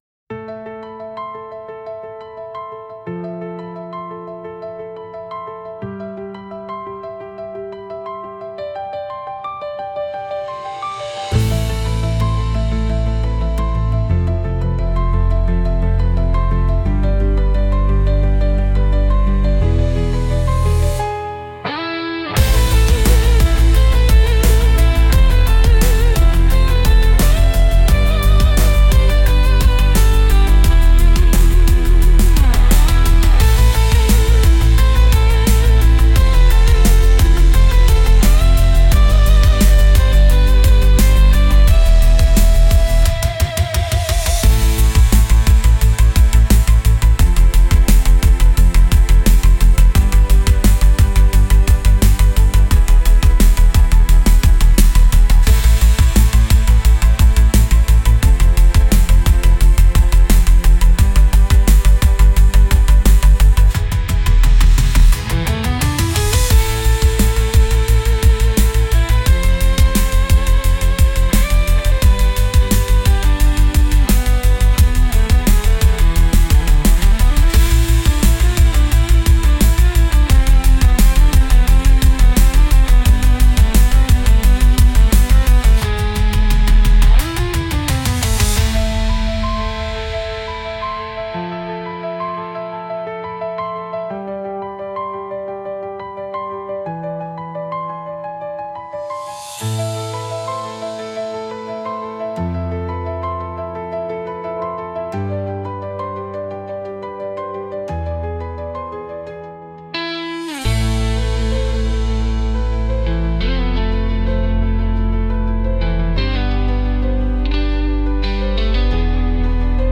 Genre: Inspirational Mood: Overthinking Editor's Choice